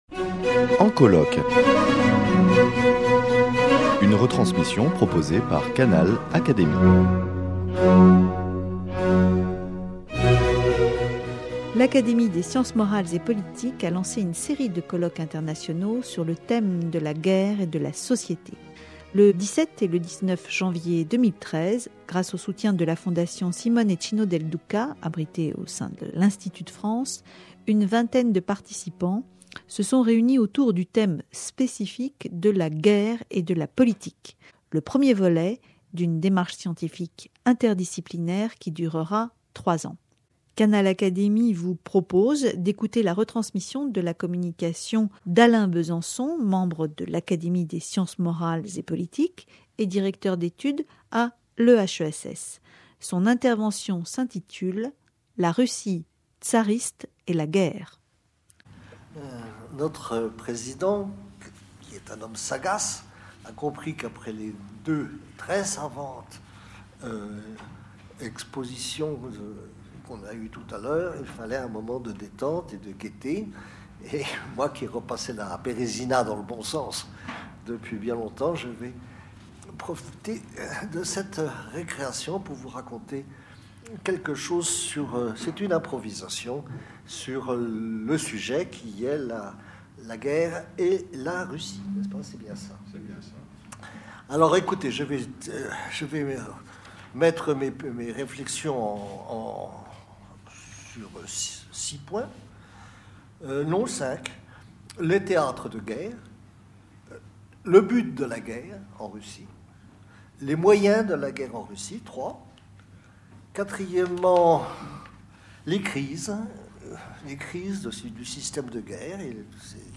Alain Besançon dresse le panorama des guerres sous la Russie tsariste dans le cadre du colloque international "Guerre et politique", initié par son confrère Jean Baechler.
Une vingtaine de participants se sont réunis autour du thème spécifique de la Guerre et de la politique, le premier volet d’une démarche scientifique interdisciplinaire qui durera trois ans.